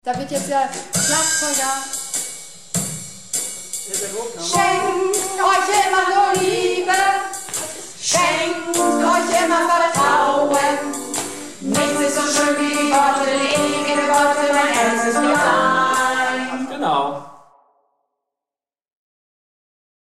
Projektchor "Keine Wahl ist keine Wahl" - Probe am 06.08.19